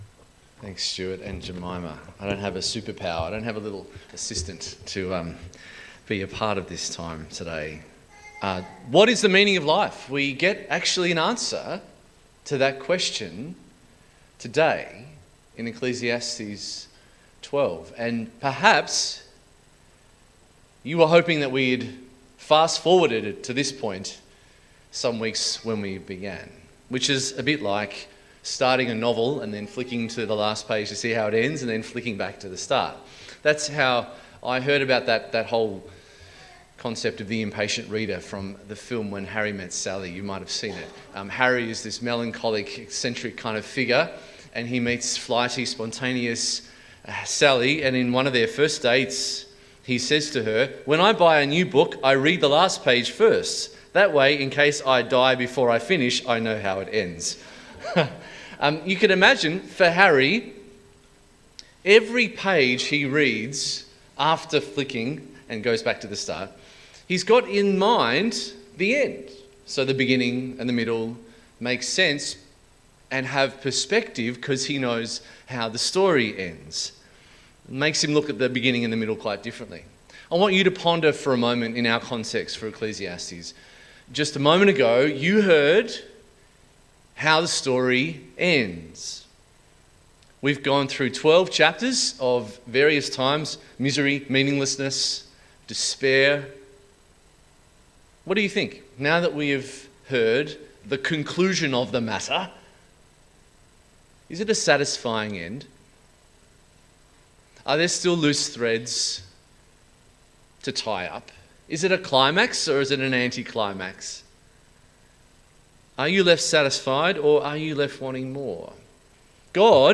The Meaning of Life Passage: Ecclesiastes 12:1-14 Service Type: 10am Service « Work and New Creation How can a loving God judge people?